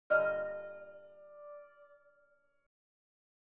Piano Nudes Mve 3 Portamento Notes.wav